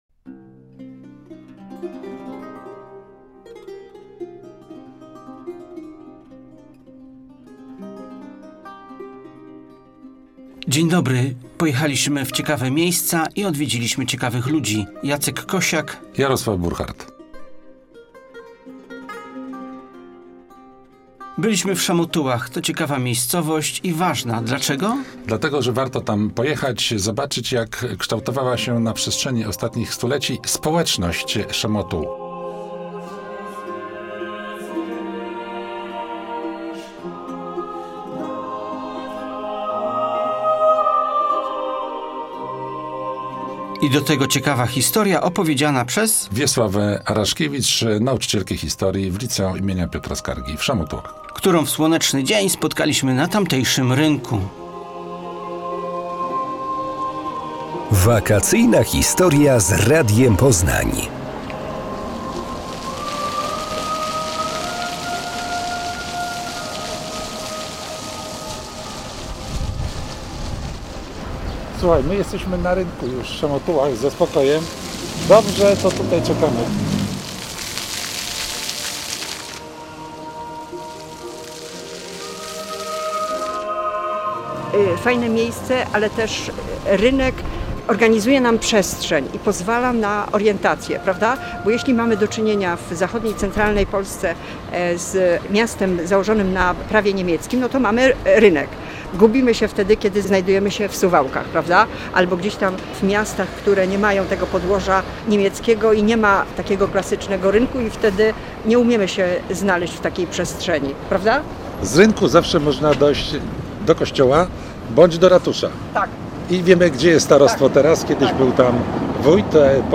Audycja
Muzyka Wacława z Szamotuł.